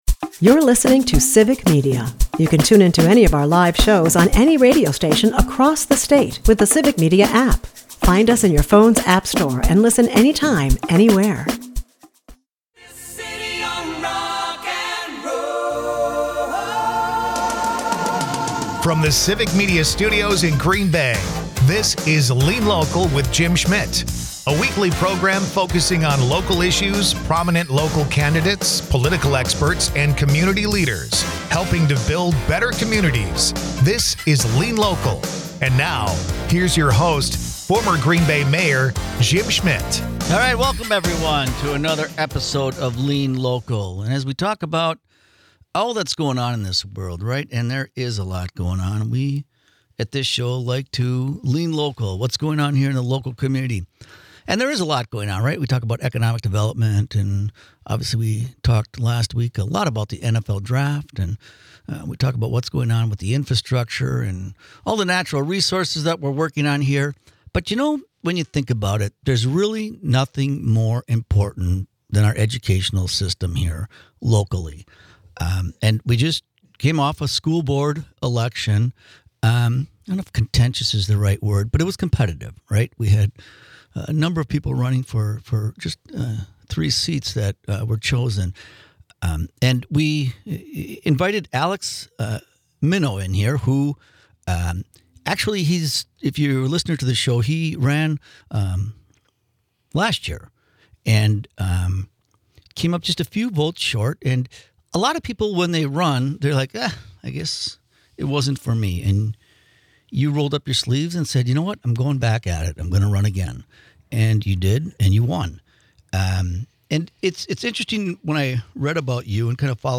Lean W/ the Green Bay School District April 13, 2025 Guest: Alex Mineau Jim Schmitt is joined by GBAPS School Board new elect., Alex Mineau. Jim and Alex talk about what is going in the the school district- Test results, truancy, and school choice. Alex shares what he is going to do to make the GBAPS great in the Green Bay community.
Dive into the heart of community issues with 'Lean Local,' hosted by former Green Bay Mayor Jim Schmitt.